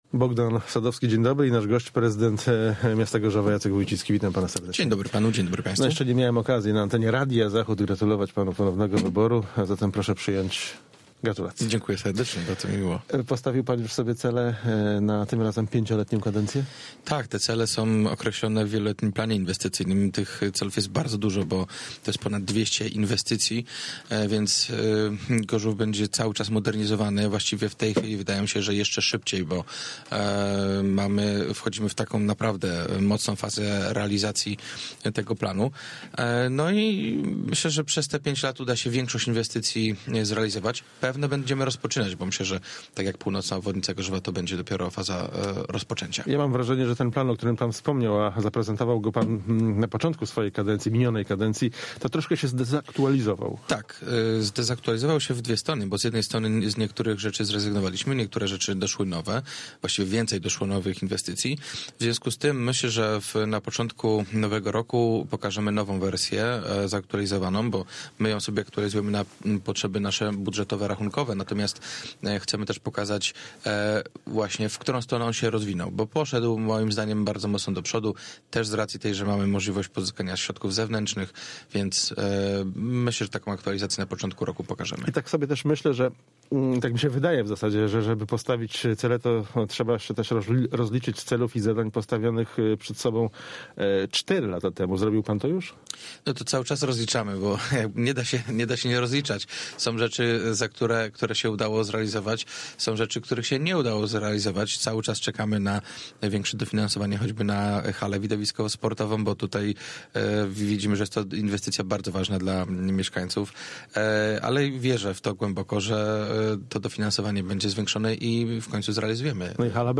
Z prezydentem Gorzowa Wlkp. rozmawia